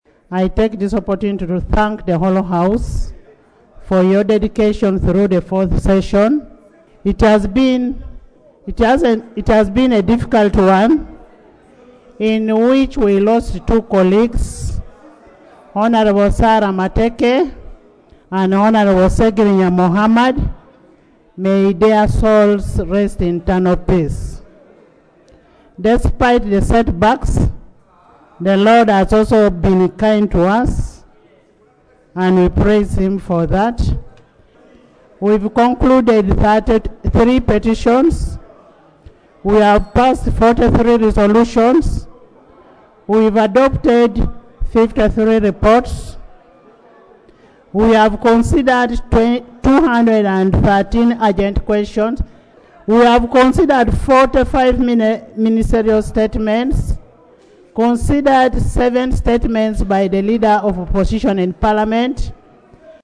Speaker Anita Among chairing the 35th and last plenary stitting of the Third Meeting of the Fourth Session of the 11th Parliament on Thursday, 29 May 2025
AUDIO: Speaker Anita Among
Anita Among prorogues House.mp3